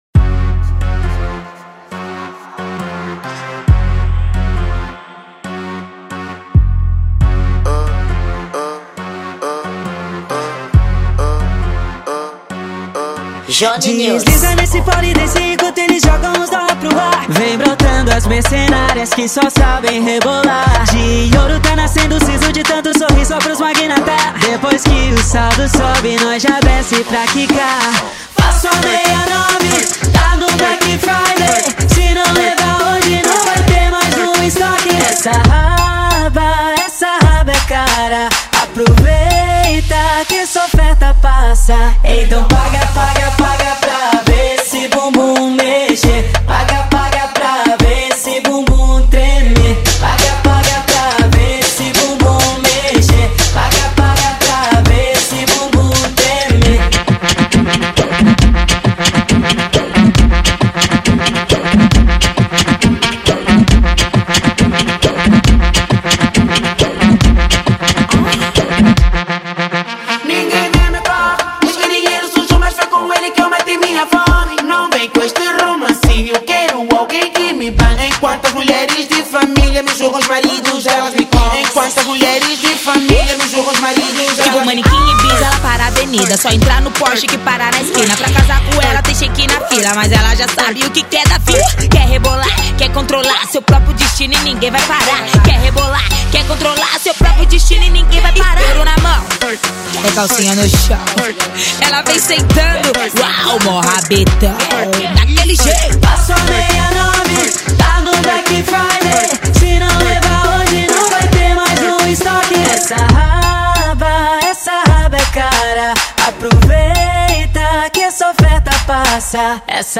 Gênero: Afro Funk